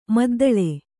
♪ maddaḷe